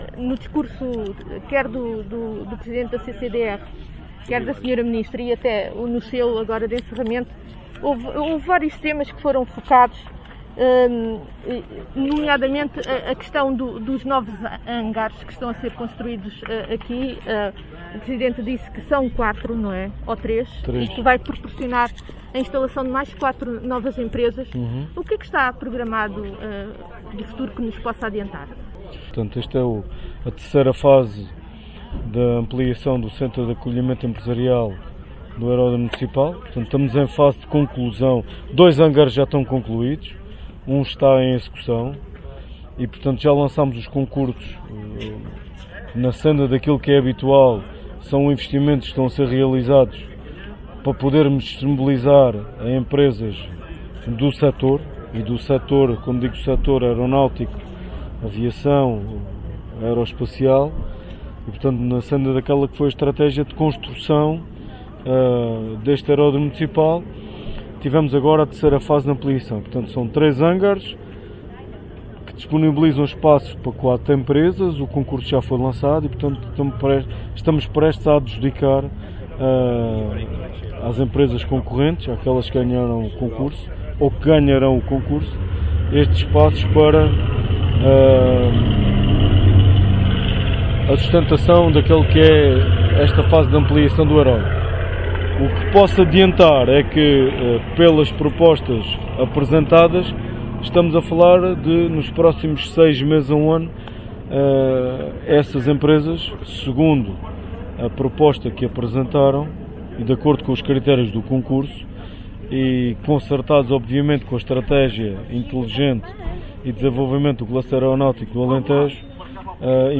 ENTREVISTA-hugo-Hilario-AIR-SUMMI.mp3